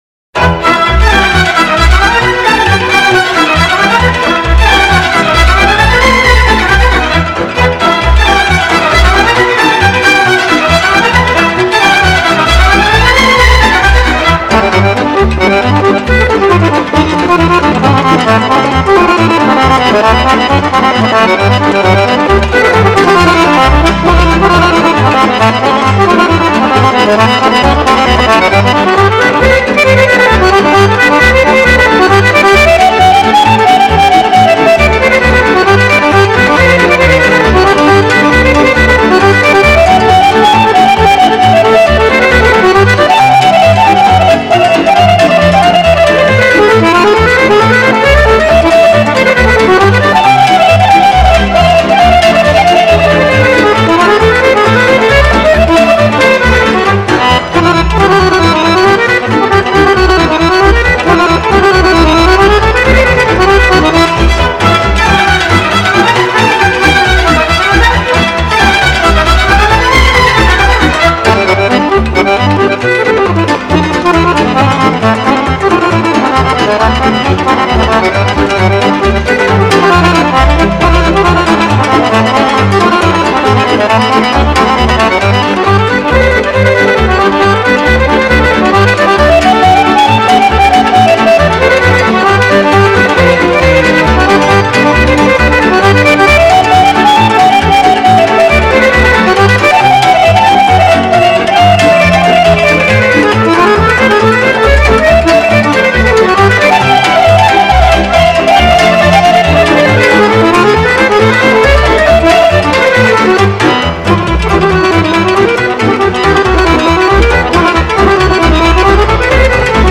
Играет молдавский аккордеонист
Народные песни и танцы